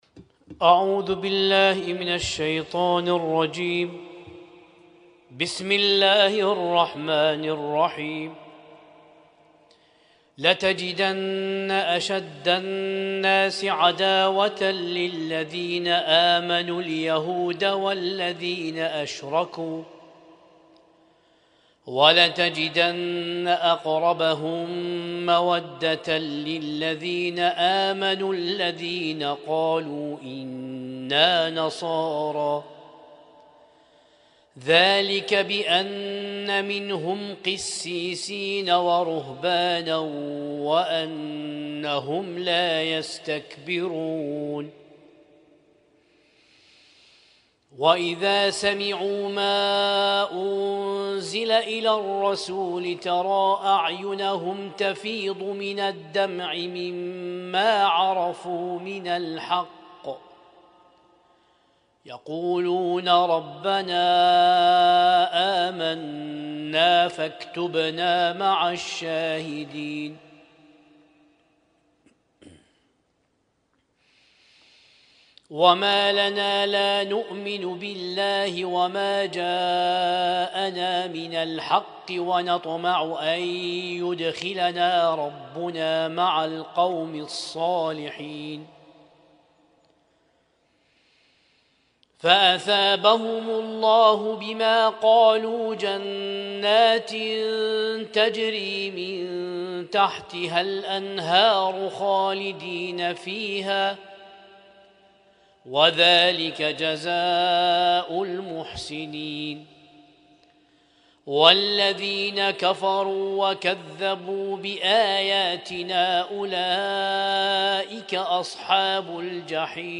Husainyt Alnoor Rumaithiya Kuwait
اسم التصنيف: المـكتبة الصــوتيه >> القرآن الكريم >> القرآن الكريم 1447